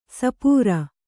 ♪ sapūra